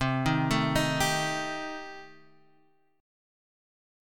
Cm6 Chord